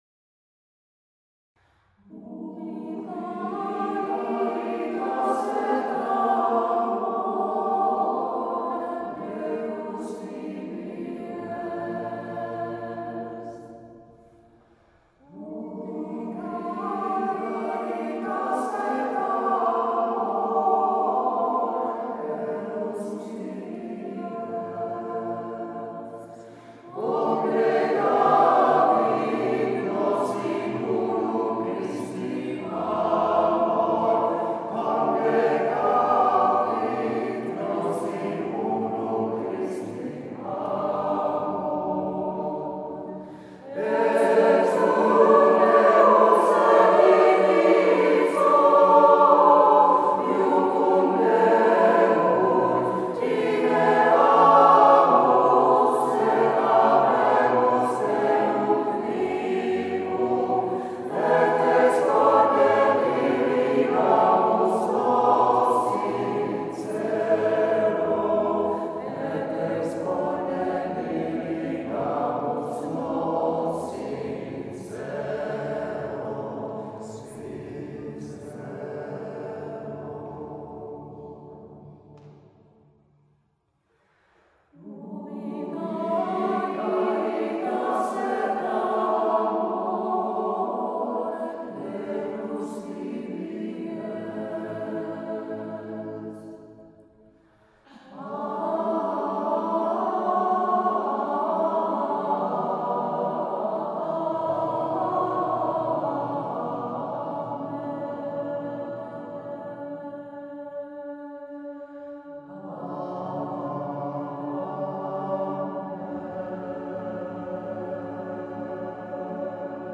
Zvuková ukázka z vystoupení ve Valle san Felice